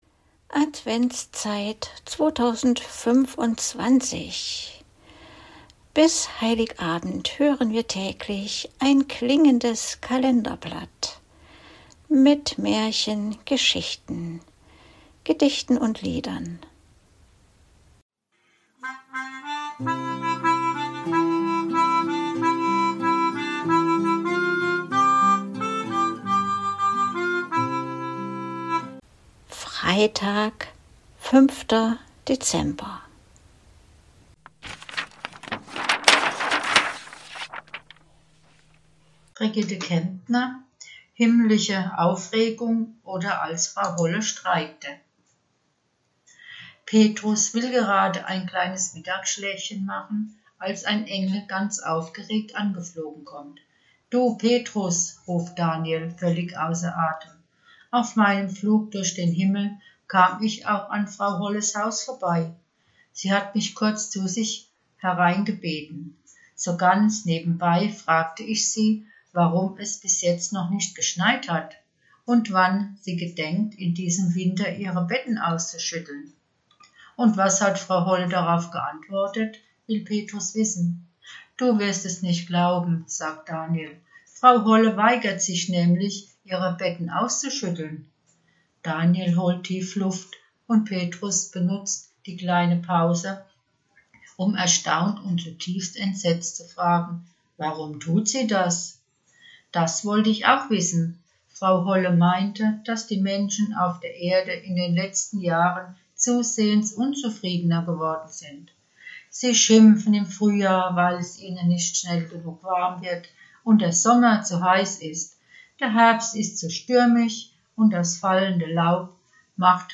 liest die von ihr selbst geschriebene Geschichte